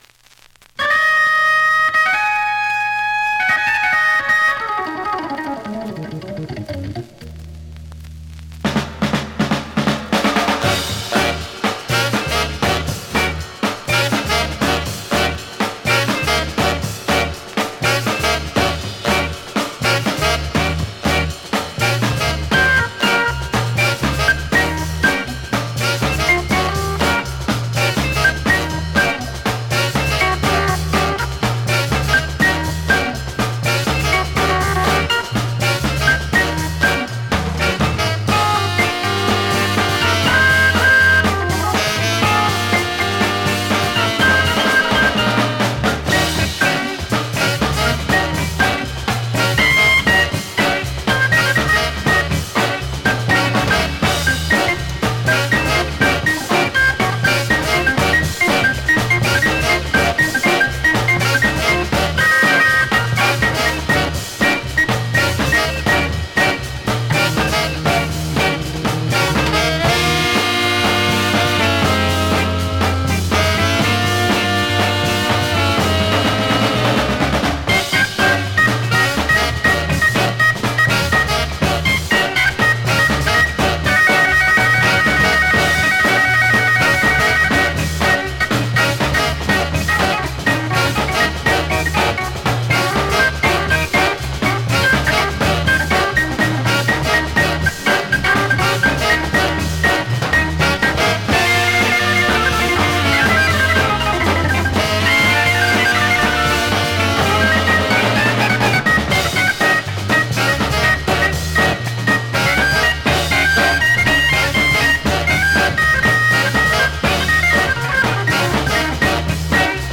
7"Single 45 RPM現物の試聴（両面すべて録音時間５分４６秒）できます。